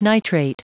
Prononciation du mot nitrate en anglais (fichier audio)
Prononciation du mot : nitrate